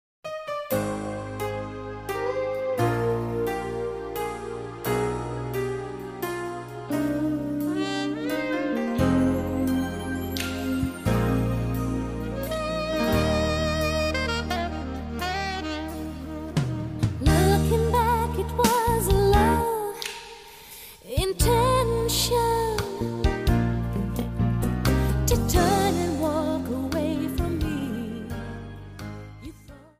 Slow Walz